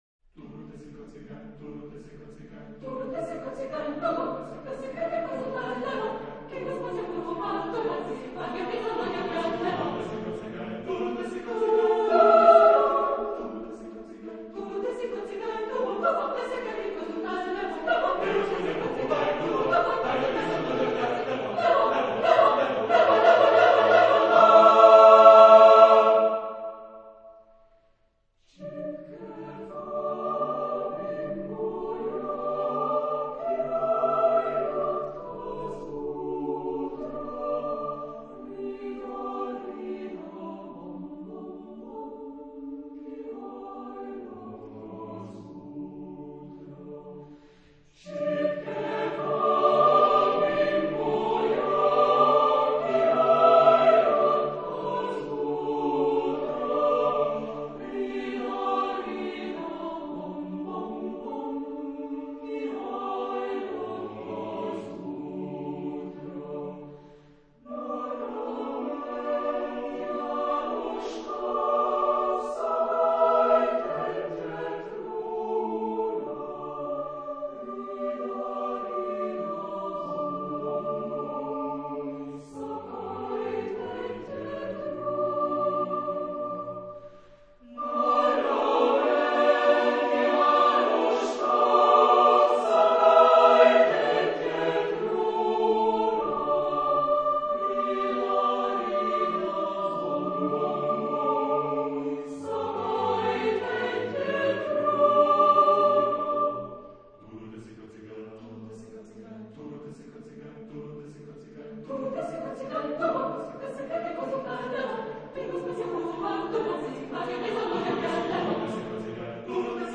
Genre-Style-Forme : Chanson ; Folklore ; Profane
Type de choeur : SSAATB  (6 voix mixtes )
Tonalité : ré majeur ; la mineur